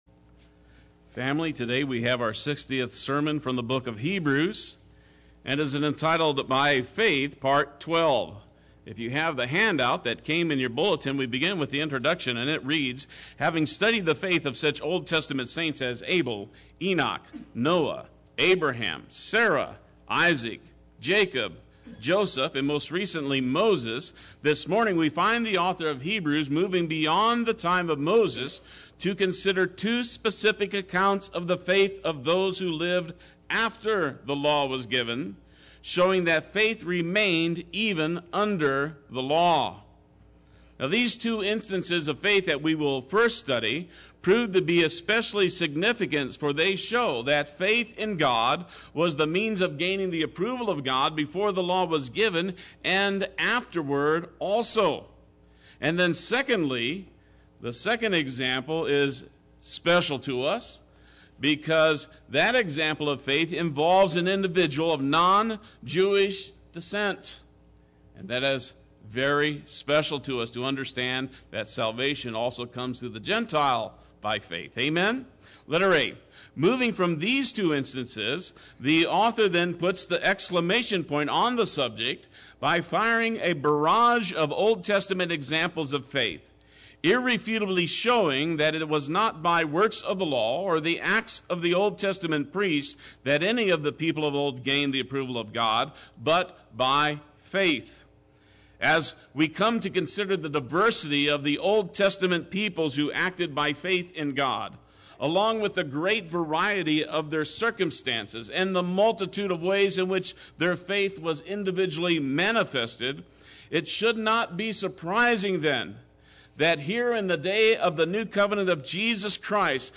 Play Sermon Get HCF Teaching Automatically.
Part 12 Sunday Worship